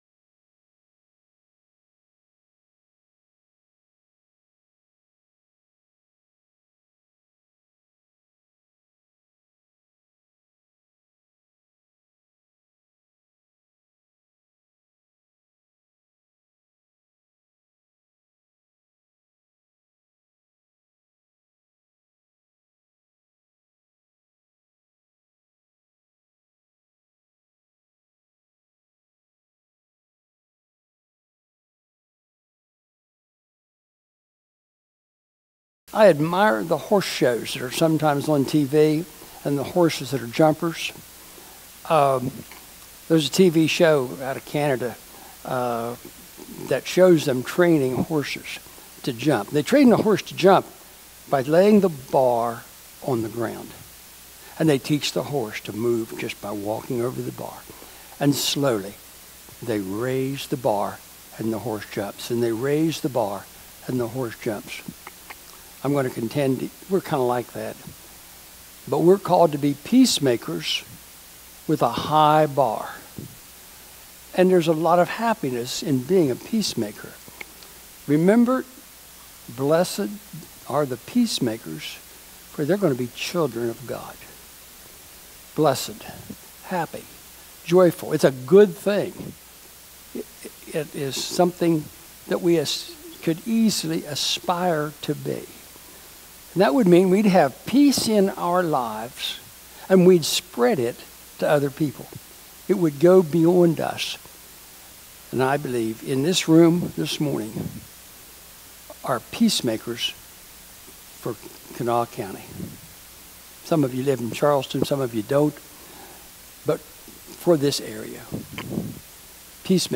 The first 35 seconds of this audio file are blank.
Due to technical difficulties communion comments and opening songs are not on the morning video [or audio].
Sermon Audio: Download